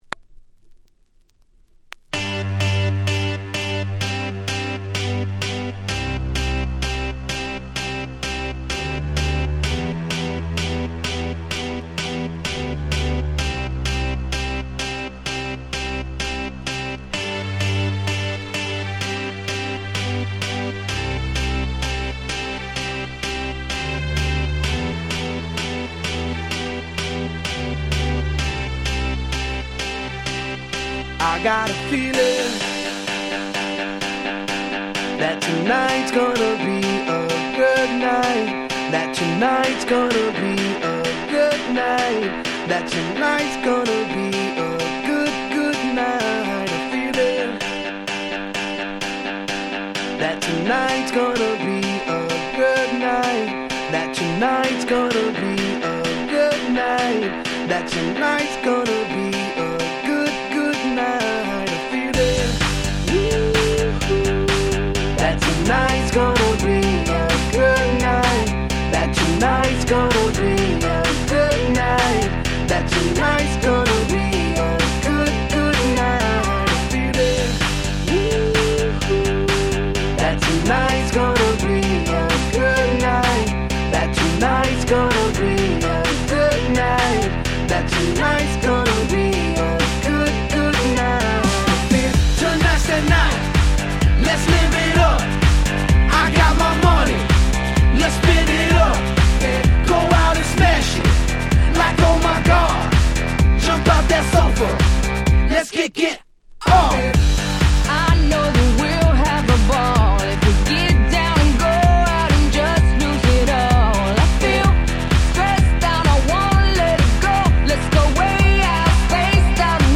みんな大好き、超絶アゲアゲクラシック！
EDM アゲアゲ パリピ キャッチー系